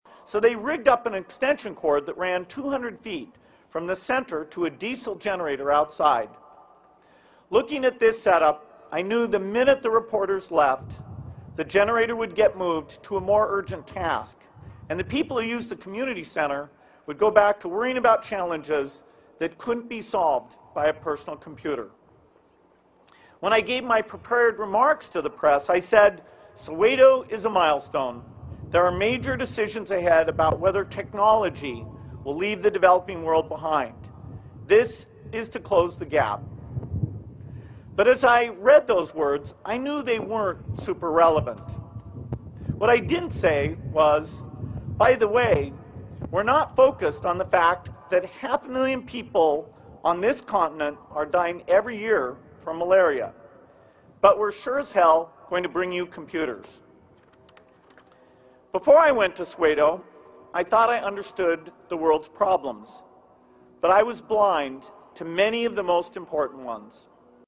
公众人物毕业演讲第26期:比尔盖茨夫妇于斯坦福大学(7) 听力文件下载—在线英语听力室